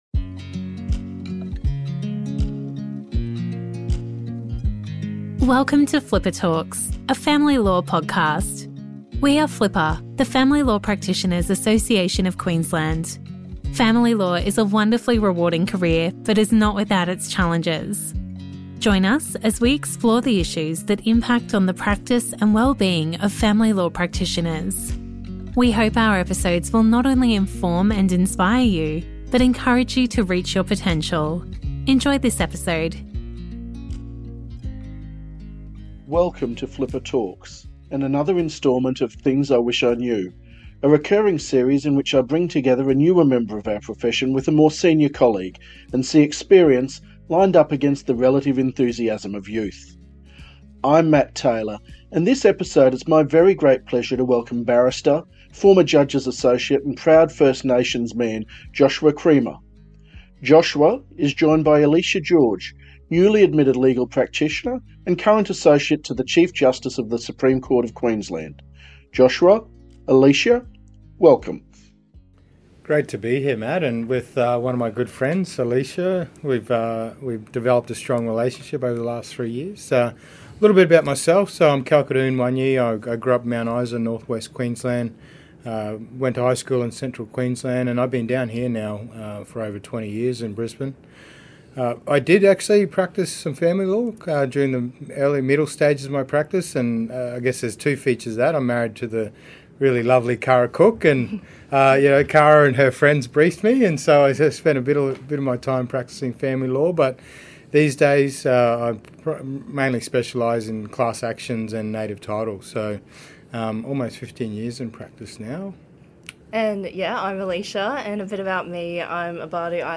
Together we discuss the importance of mentorship, representation and community. This is a special podcast episode with two First Nations practitioners and is not to be missed.